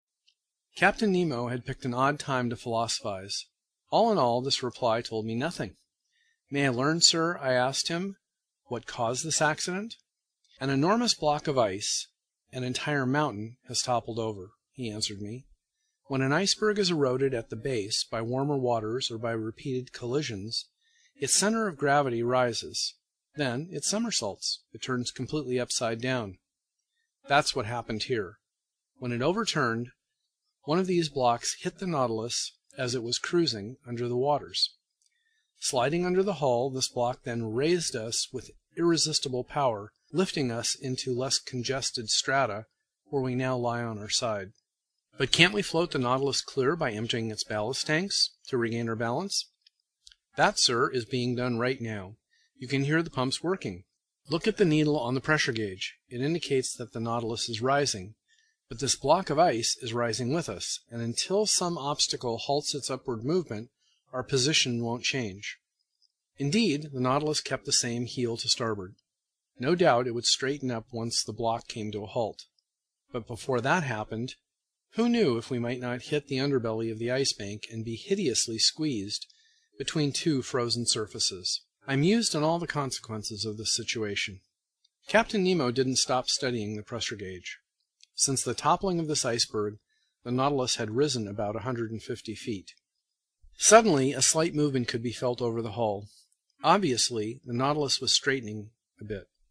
英语听书《海底两万里》第463期 第28章 惊奇还是意外(5) 听力文件下载—在线英语听力室
在线英语听力室英语听书《海底两万里》第463期 第28章 惊奇还是意外(5)的听力文件下载,《海底两万里》中英双语有声读物附MP3下载